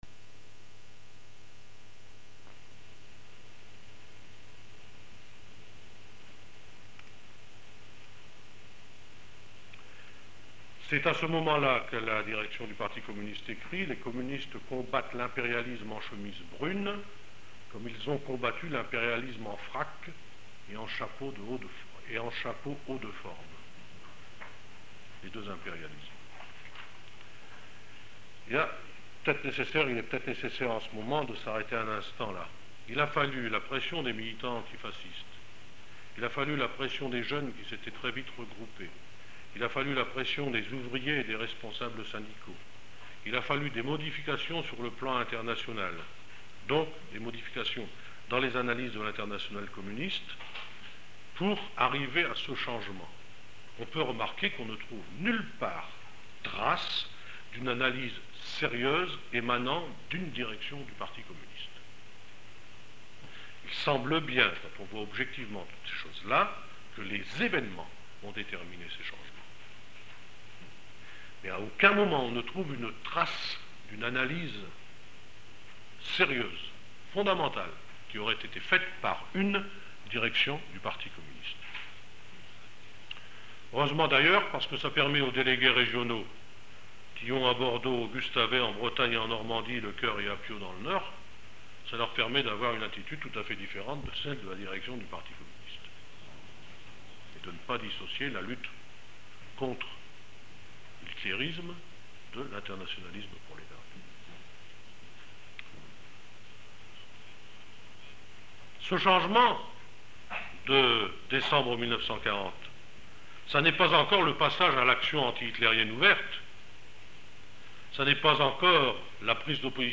Rencontre du cycle d’étude Histoire du mouvement ouvrier 1975-1976 du Centre populaire de formation socialiste. 1.